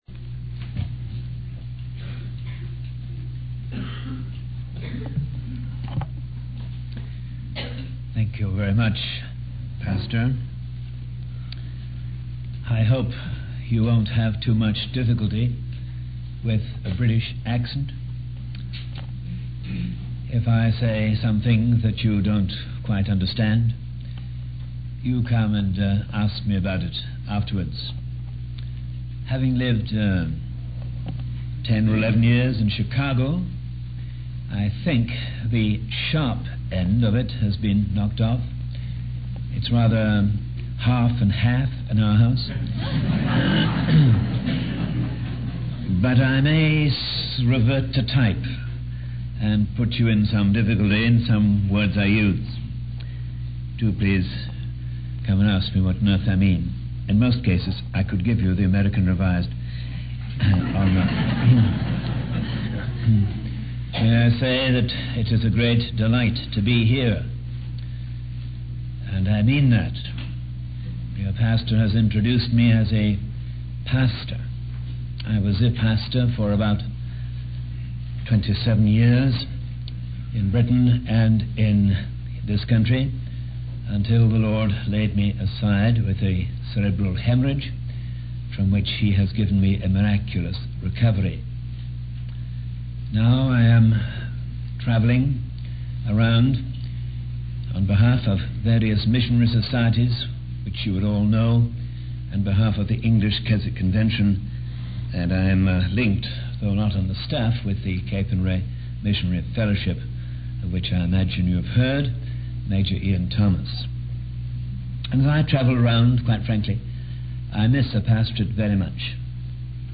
In this sermon, the speaker emphasizes the need for a different approach to church programs and gatherings. He highlights the story of Gideon and how God used a small number of men to defeat a much larger army. The speaker encourages believers to fully surrender their lives to God and allow Him to work through them in their daily interactions with others.